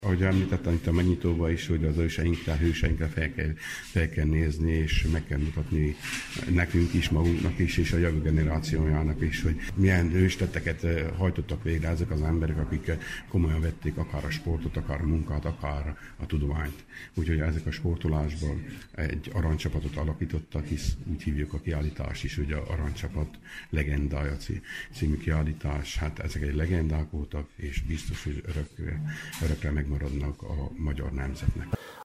A kiállítás házigazdája Albert Tibor Tusnádfürdő polgármestere.